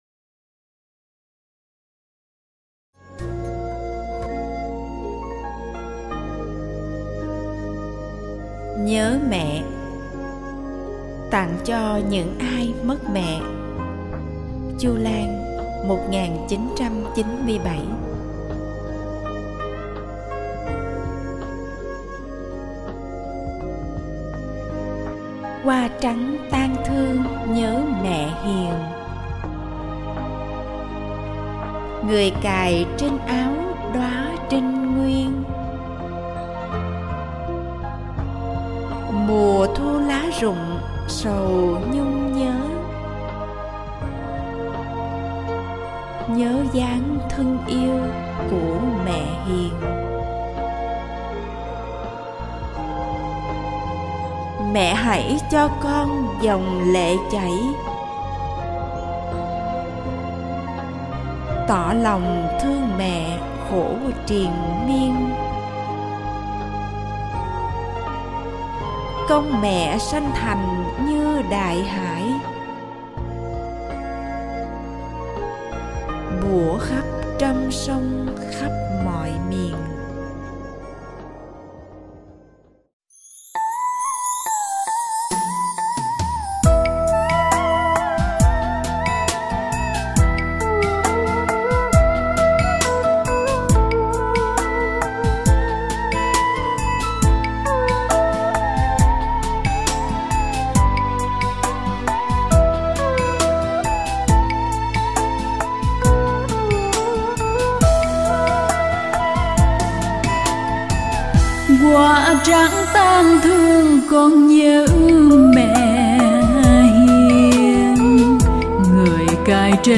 THƠ VÀ NHẠC